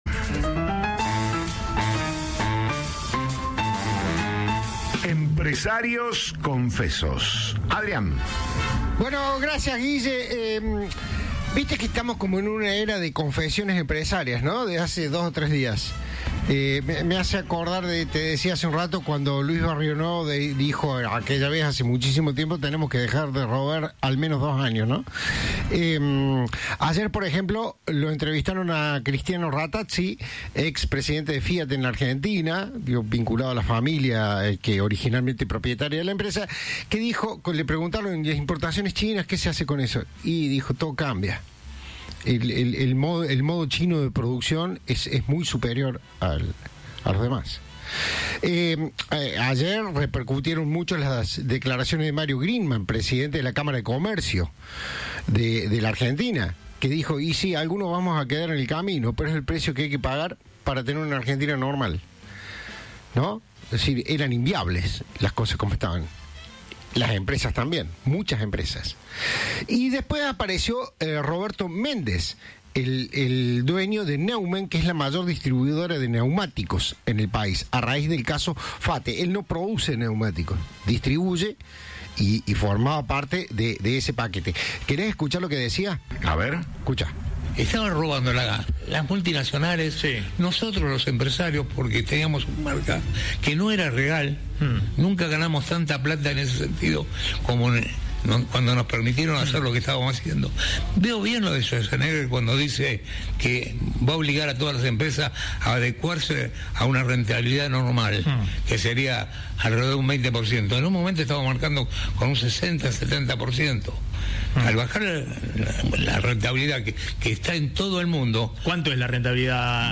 Lo afirmó el ministro de Trabajo, Juan Pusineri, a Cadena 3 Rosario, luego de que a nivel nacional trascendiera que es una medida que está en carpeta. Además, habló del conflicto de Uocra con ASSA.